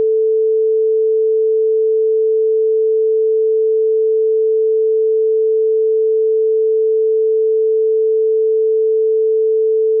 front_sin440_ambiX.wav